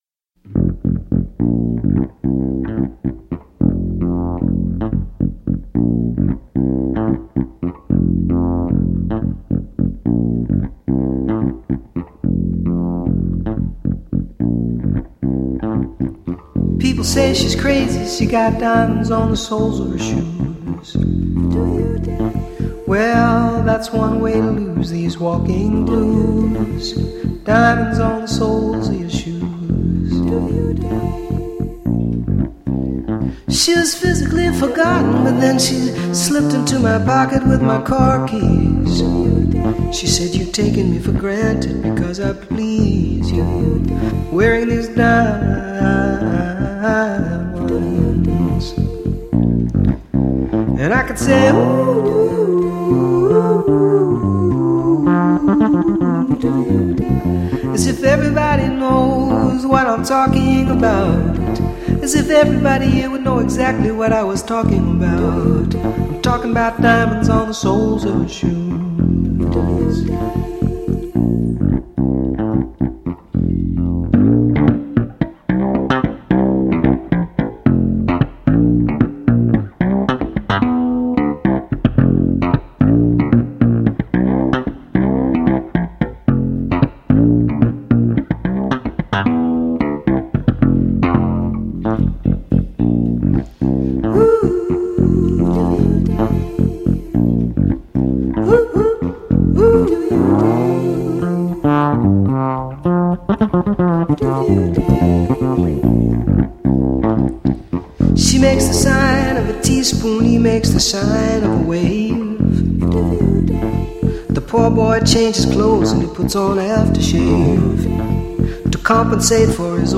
isolates just the bass and vocals